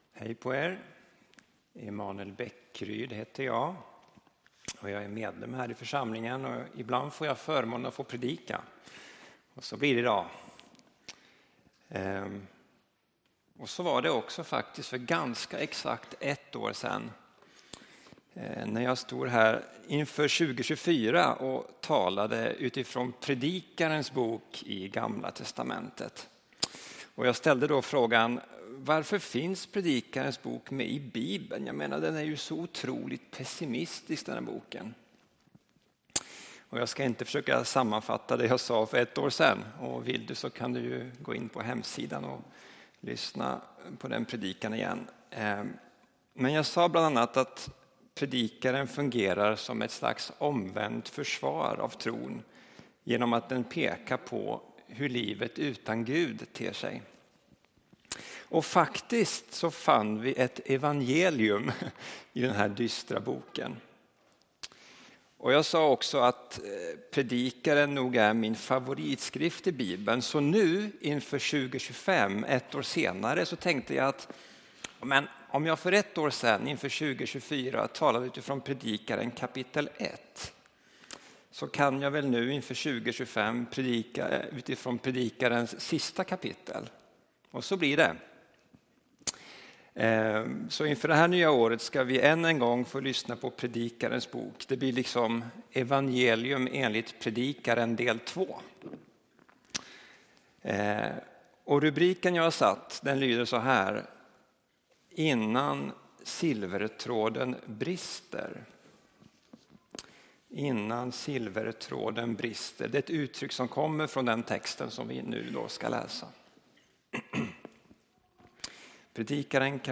Här publiceras inspelade predikningar från gudstjänster i Ryttargårdskyrkan, Linköping.